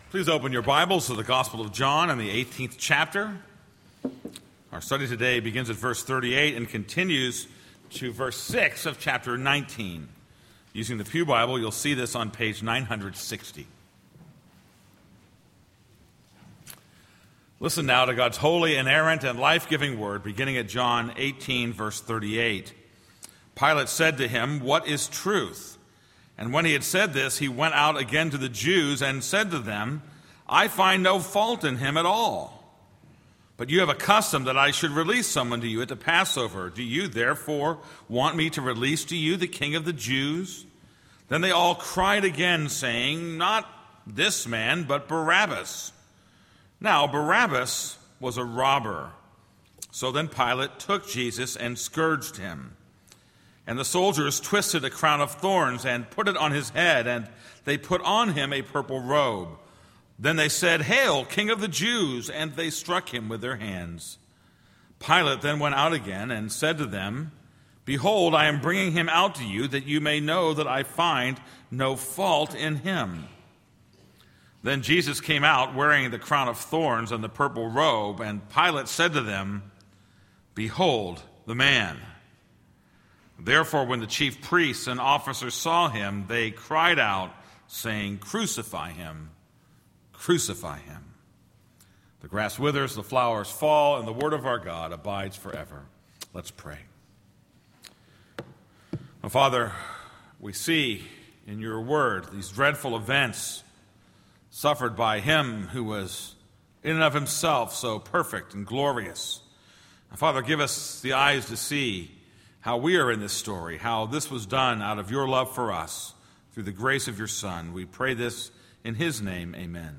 This is a sermon on John 18:38b-19:8.